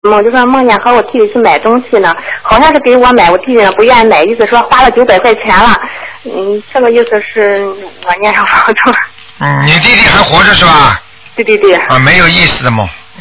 目录：2012年03月_剪辑电台节目录音集锦